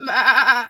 pgs/Assets/Audio/Animal_Impersonations/sheep_2_baa_06.wav at master
sheep_2_baa_06.wav